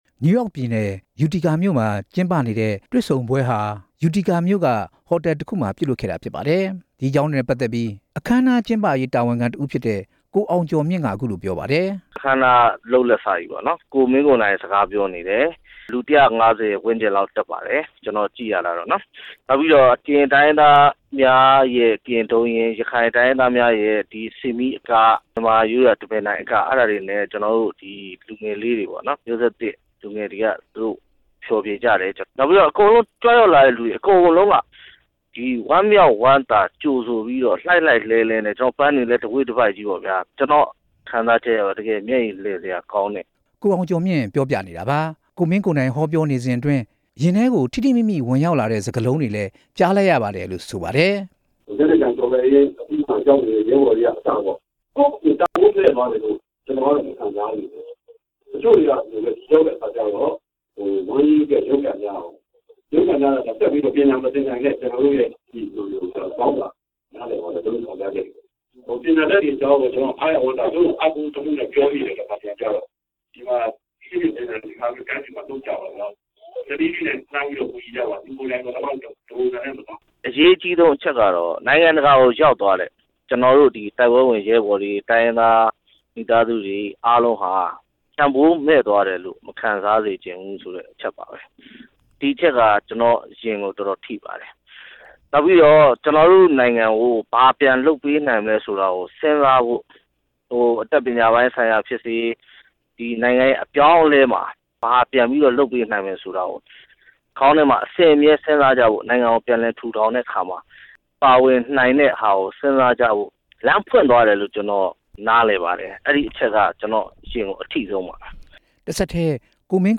ကိုမင်းကိုနိုင်နဲ့ ကိုကိုကြီးတို့ရဲ့ ဟောပြောပွဲကို နယူးယောက်ပြည်နယ် အထက်ပိုင်းက မြန်မာတွေ လာရောက်စုဝေးကြတာပါ။